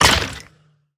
Minecraft Version Minecraft Version 25w18a Latest Release | Latest Snapshot 25w18a / assets / minecraft / sounds / mob / wither_skeleton / hurt2.ogg Compare With Compare With Latest Release | Latest Snapshot
hurt2.ogg